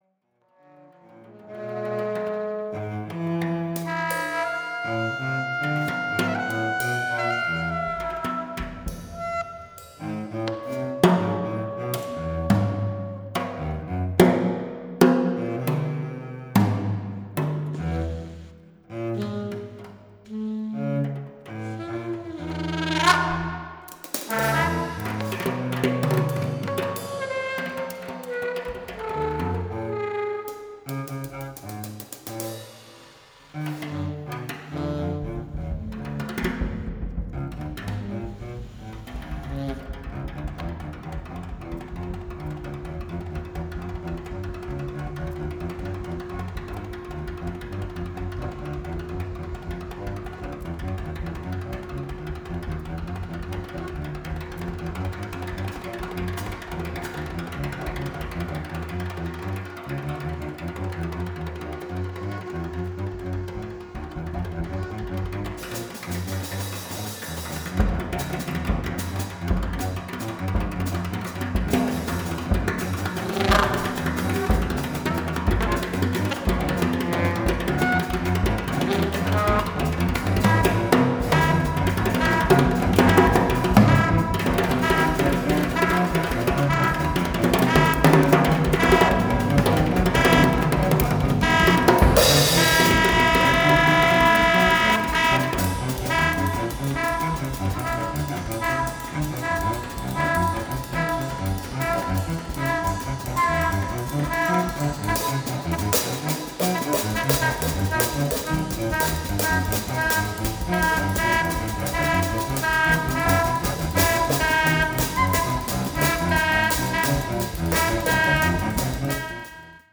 cello
alto sax